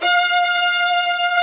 STR VIOLA07R.wav